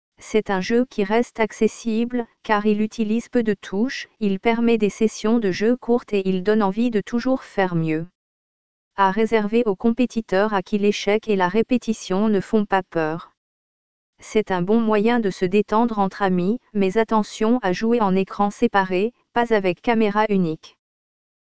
L’ambiance sonore